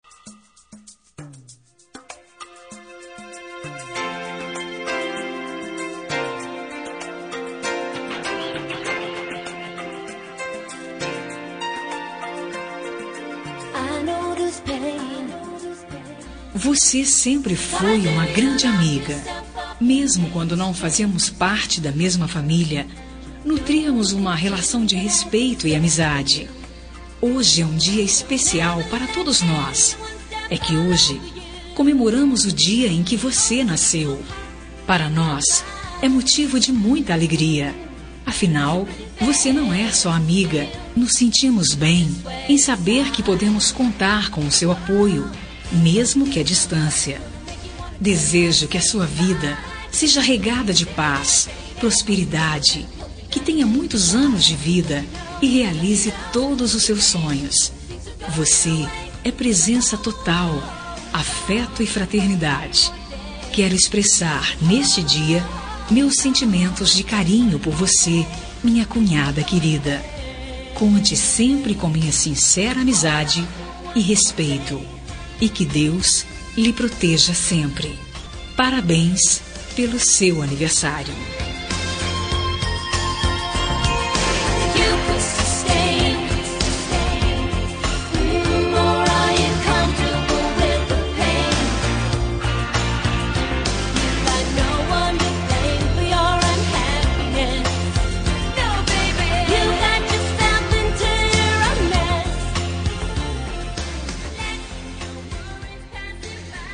Aniversário de Cunhada – Voz Masculina – Cód: 2626